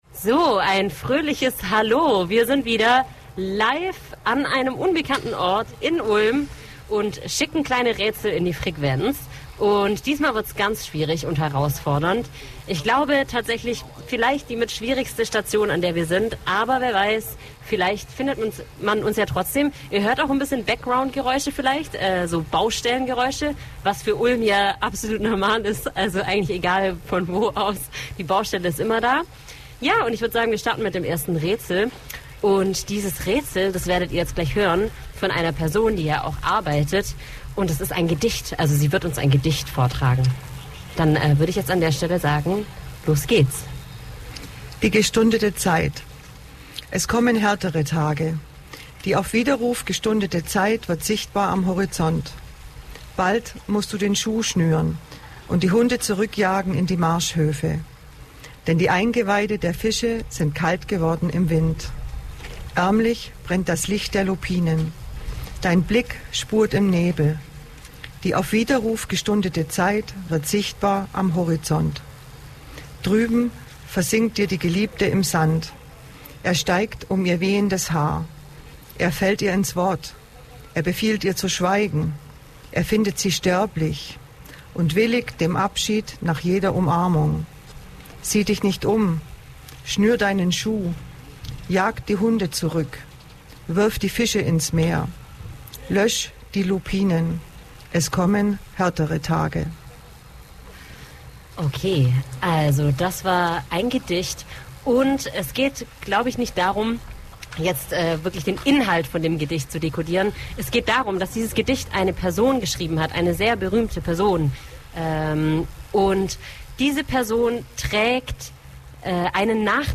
Wunderschöne Radiomomente live und direkt von der Straße, tolle Begegnungen, kalte Nasen, überraschende Sonnenstrahlen und herausfordernde Verkehrssituationen.
Um 14:00 Uhr wurde das Vélo herzlichst vor dem Reha Café vom RehaVerein empfangen. Wieder durften die Zuhörer durch Rätsel erraten wo wir uns gerade befinden, dabei gab es neben Baustellengeräuschen auch witzige Sounds aus dem Tätigkeitsbereich des RehaVereins.